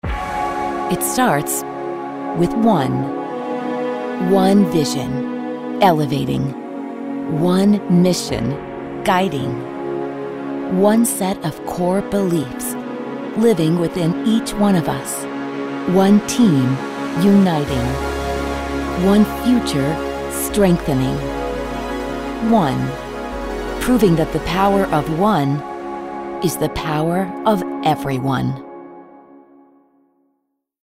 announcer, compelling, confident, corporate, friendly, inspirational, motivational